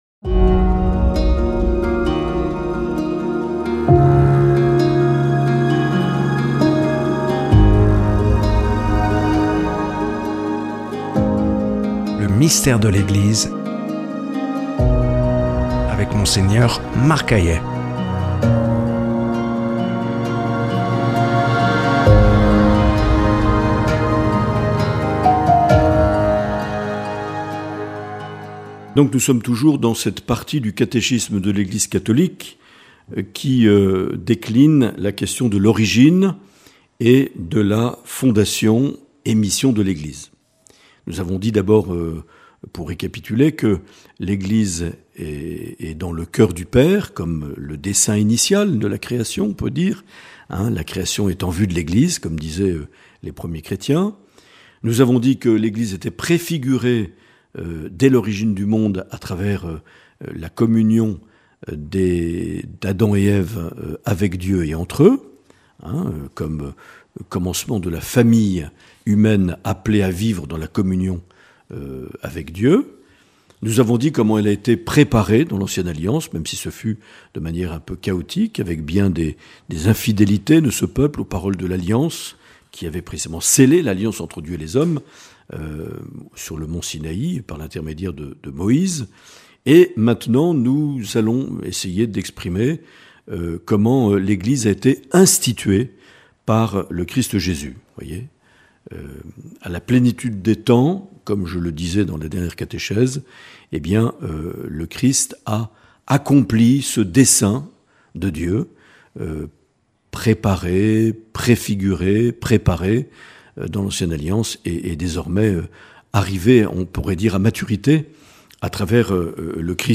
Mgr Marc Aillet nous propose une série de catéchèses intitulée "Le Mystère de l’Eglise" notamment à la lumière de la constitution dogmatique "Lumen Gentium" du concile Vatican II.